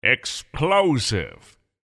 voice_tier6_explosive.mp3